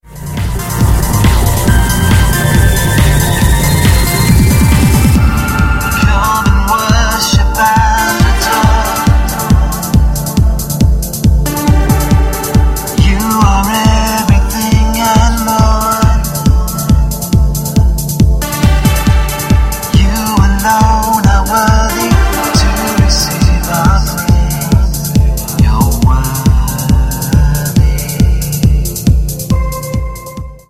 A Spititual Mix of Dance, Trance, Stadium and Club
• Sachgebiet: Dance